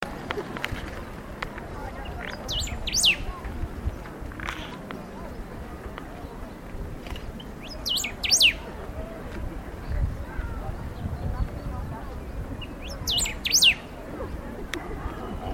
Se observaron machos y Hembras , el que canta es un Macho Adulto
Sex: Male
Life Stage: Adult
Location or protected area: Reserva Ecológica Costanera Sur (RECS)
Condition: Wild
Certainty: Observed, Recorded vocal